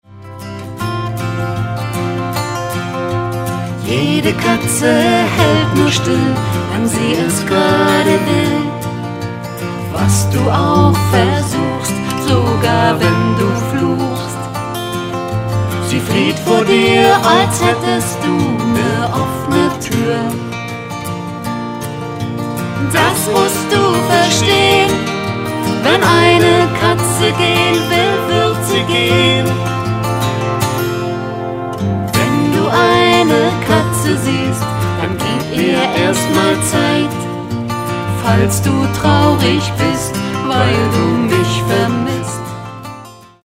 Und sich am Ende für 21 Balladen entschieden.